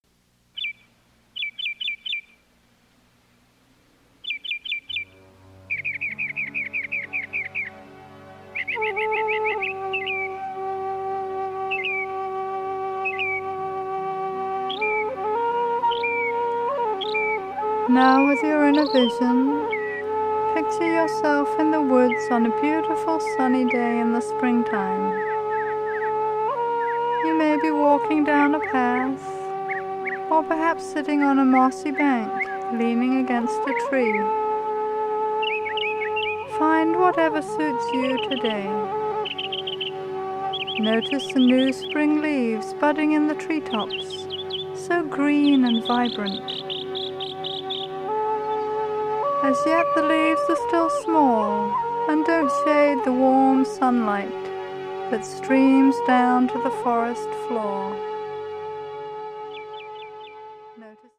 Seasons for Healing: Spring (Guided Meditation)
Piano and Synthesizer
Flute